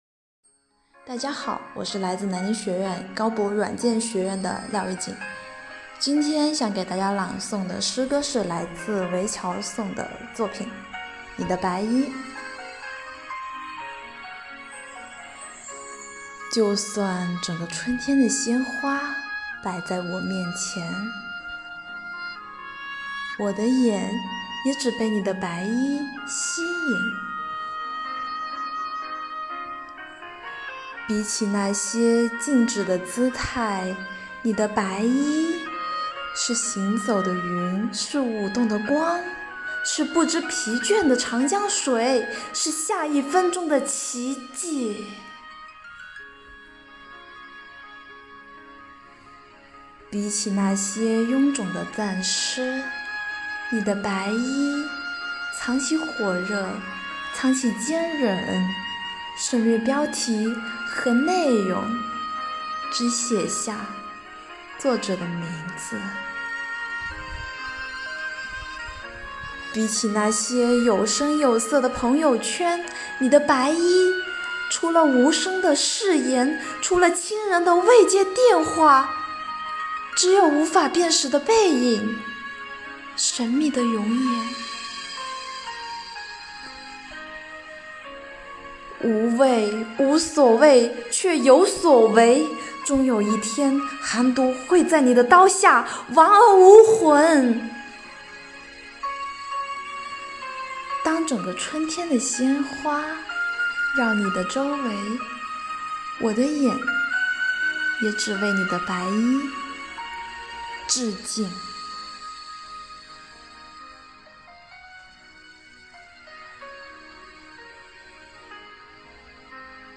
征集展示|“诵广西佳作 为抗疫发声”网络诵读比赛优选作品展播